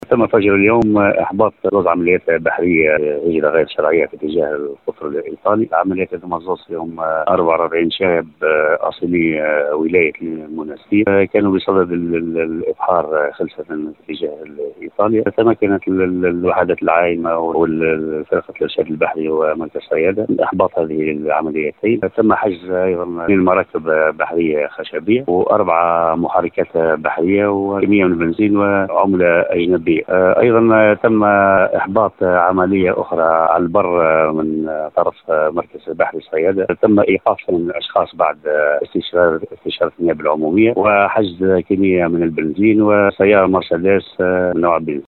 في تصريح ل”ام اف ام”